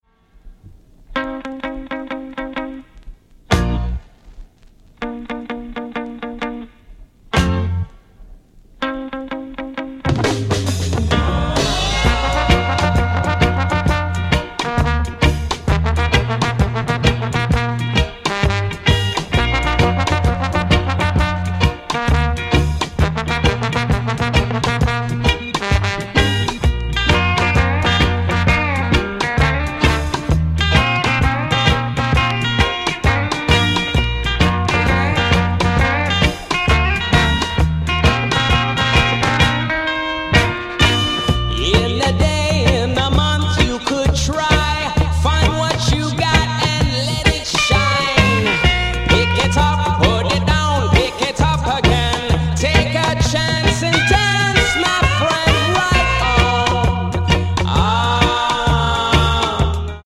Dub. Roots. Reggae. Calypso. Ska. 2 Tone.